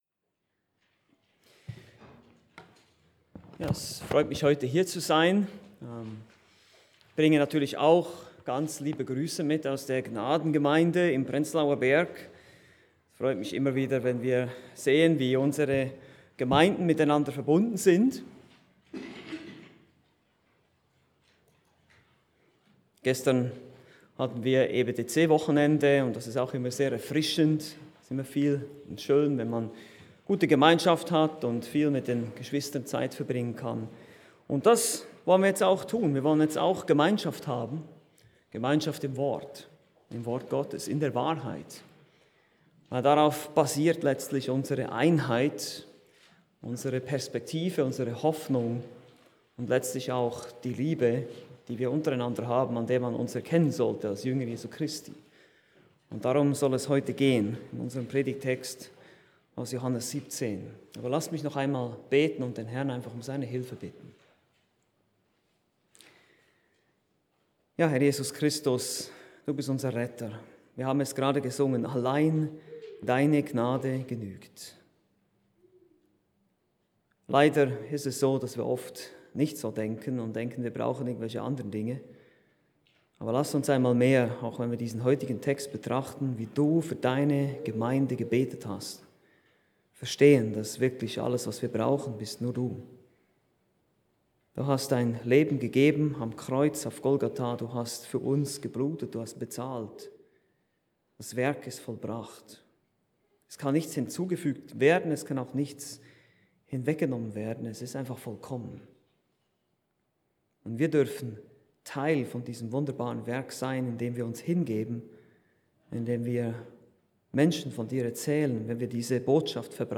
Gastprediger